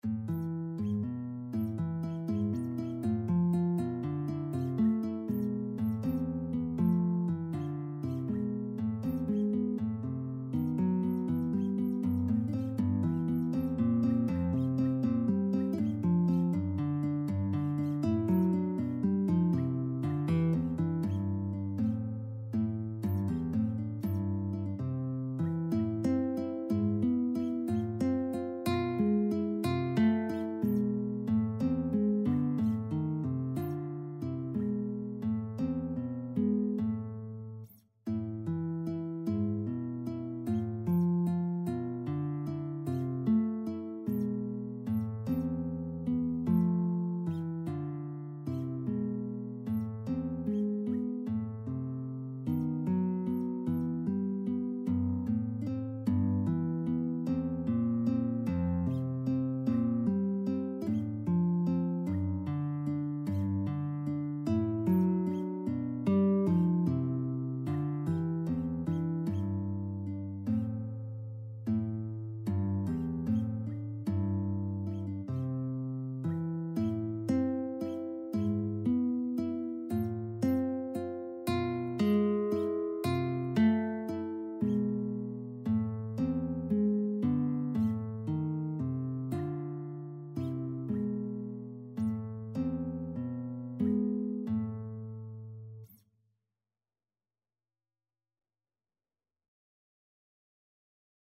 Classical Scarlatti, Alessandro O cessate di piagarmi (O no longer see to pain me) Guitar version
C major (Sounding Pitch) (View more C major Music for Guitar )
Andante con moto La prima volta .=80 (View more music marked Andante con moto)
6/8 (View more 6/8 Music)
E3-G5
Guitar  (View more Intermediate Guitar Music)
Classical (View more Classical Guitar Music)